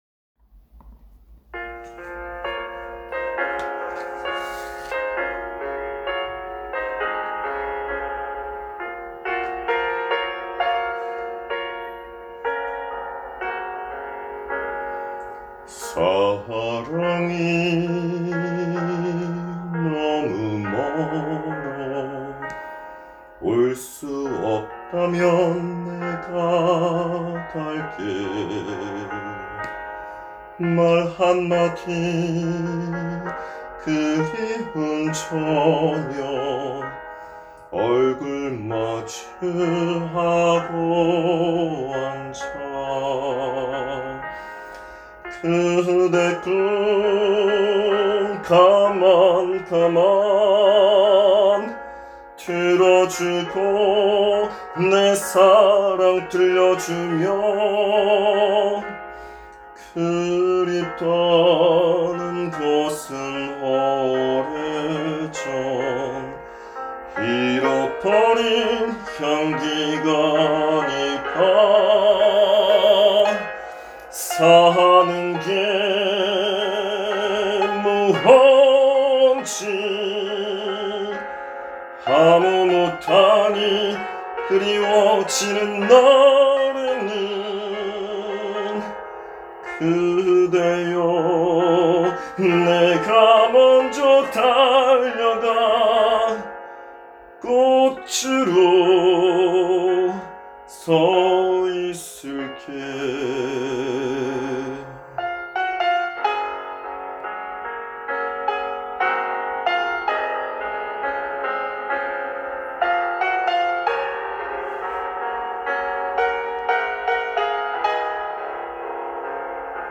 이 노래를 처음 피아노 반주로 부르면서 마음이 무너질 것 같았습니다.